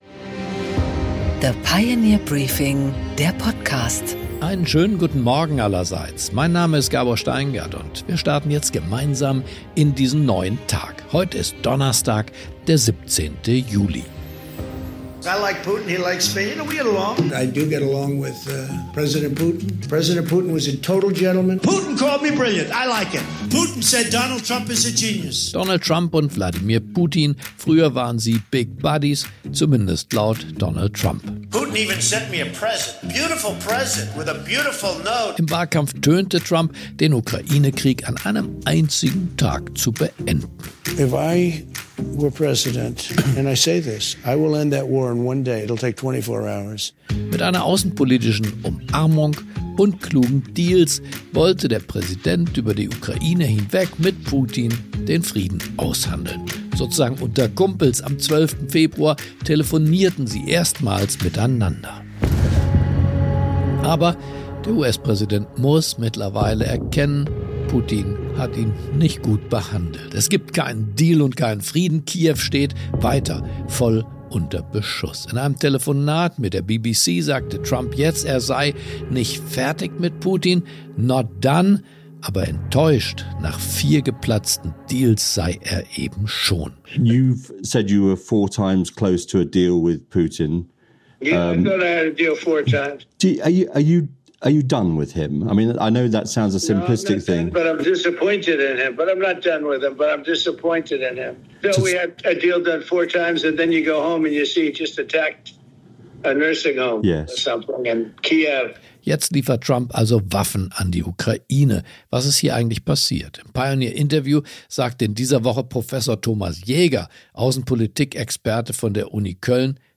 Gabor Steingart präsentiert das Pioneer Briefing
Im Gespräch: